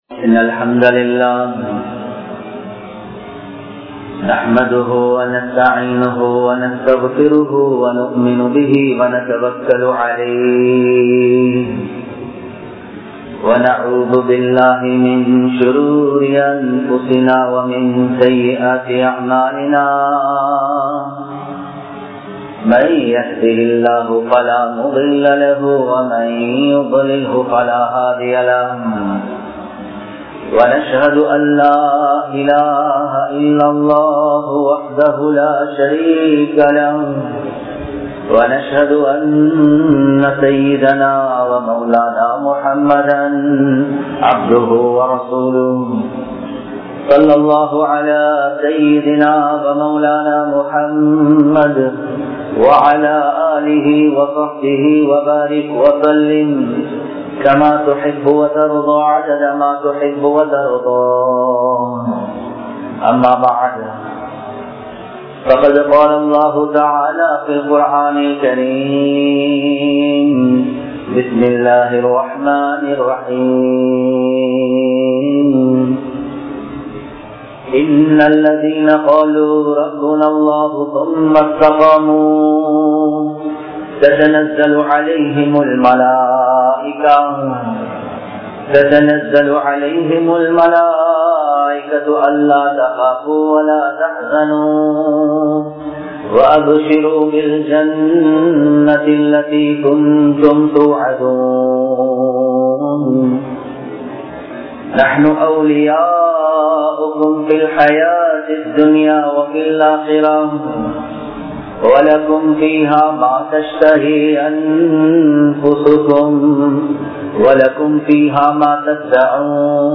Thaqwa Entraal Enna? (தக்வா என்றால் என்ன?) | Audio Bayans | All Ceylon Muslim Youth Community | Addalaichenai
Kollupitty Jumua Masjith